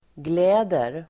Uttal: [gl'ä:der]